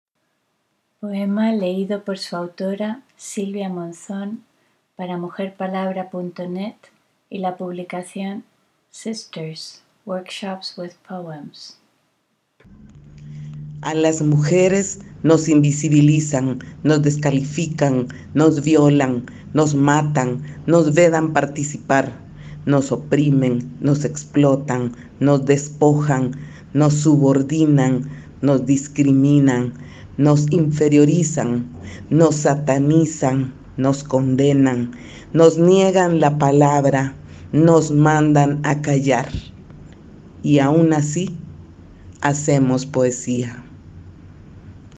Escuchar Escuchar el poema en voz de su autora